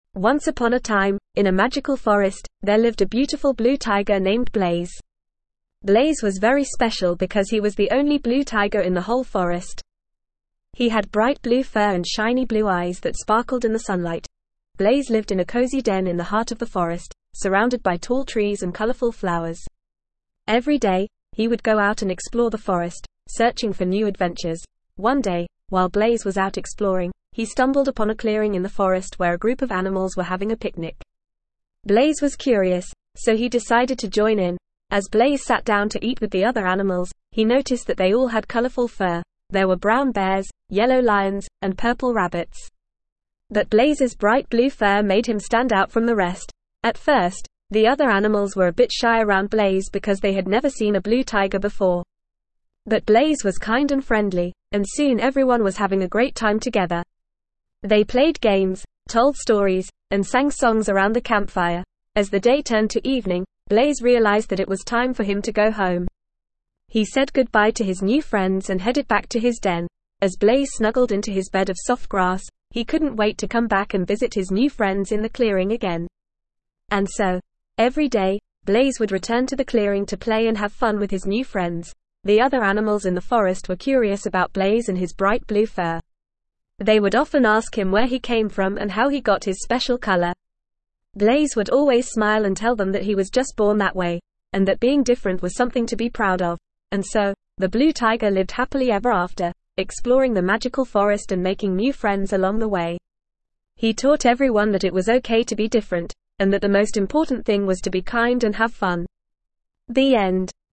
Normal
ESL-Short-Stories-for-Kids-NORMAL-reading-Blaze-the-Blue-Tiger.mp3